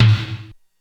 Index of /90_sSampleCDs/300 Drum Machines/Korg DSS-1/Drums02/01
LoTom.wav